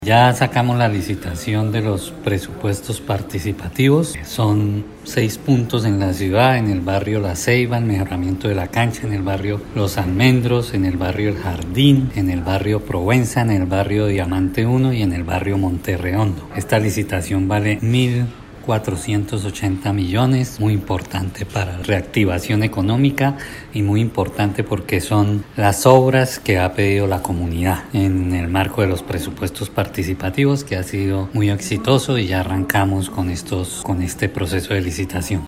Descargue audio: Iván Vargas, secretario de Infraestructura